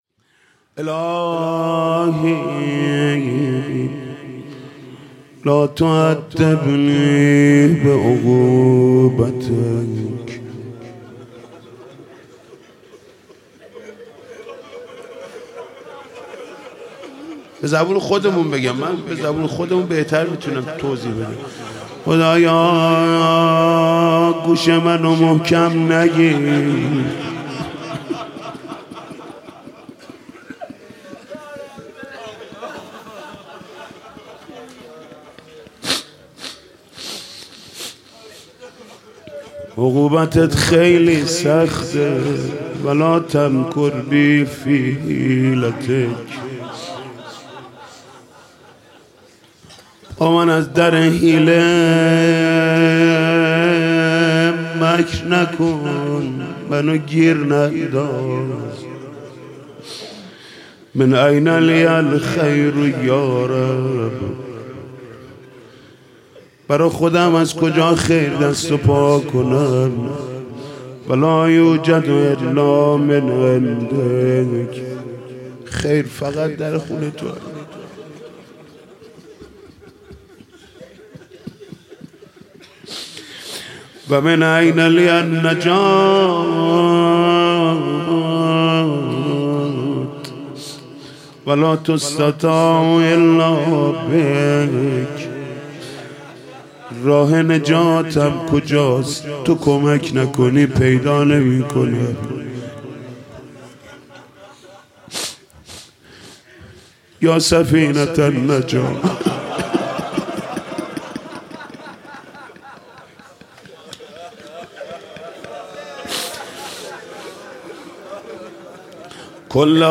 «شب نوزدهم» قرائت فرازی از دعای ابوحمزه ثمالی